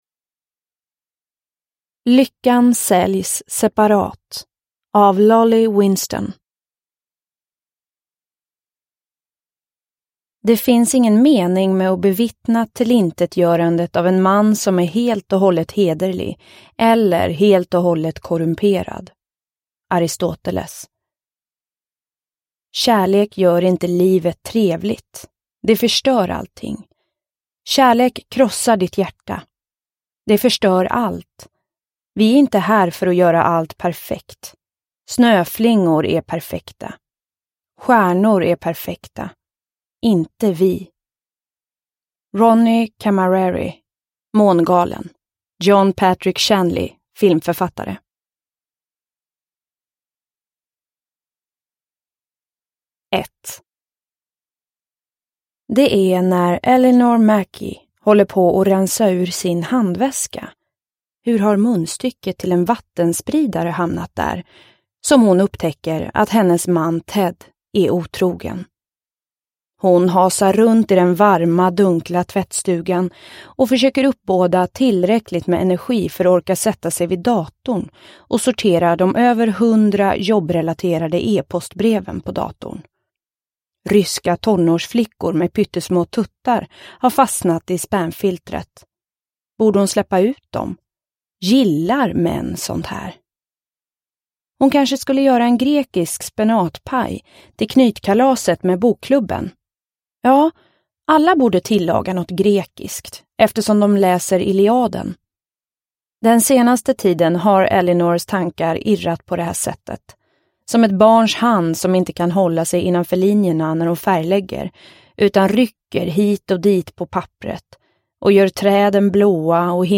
Lyckan säljs separat – Ljudbok – Laddas ner